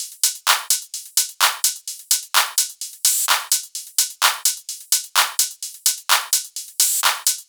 VFH1 128BPM Northwood Kit 5.wav